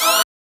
HIT 7 .wav